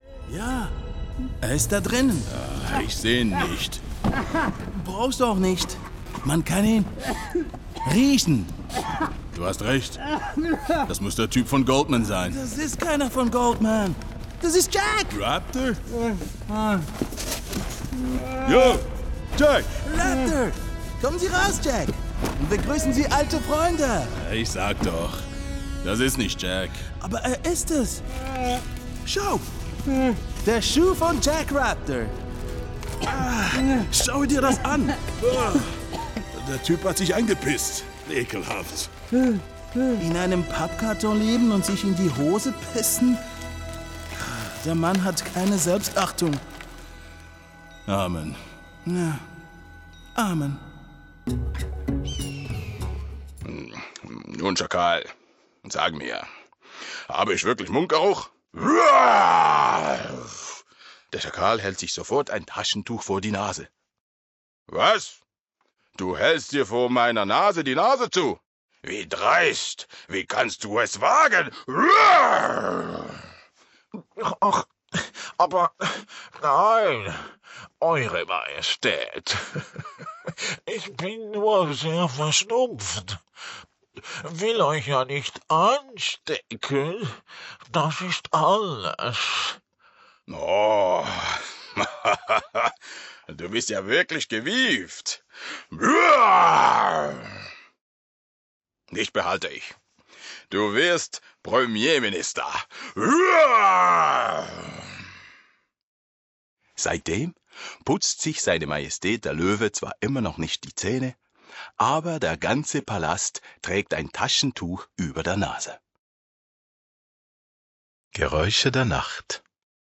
VOICE DEU /
Voice: tenor
Voice Character: warm, deep, clear